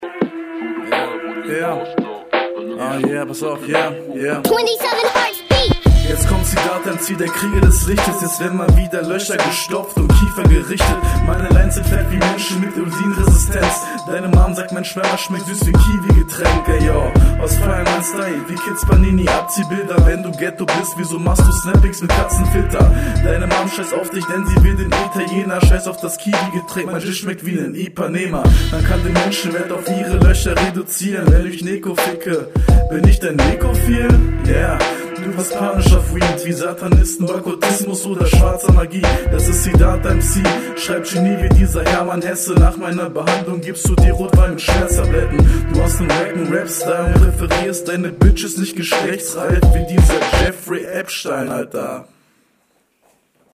Linetechnisch besser als deine RR, aber wegen sehr schlimmen Aussetzern zwischenzeitlich doch nur 1/10